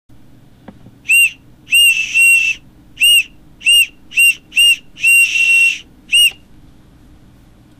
Halloween noisemakers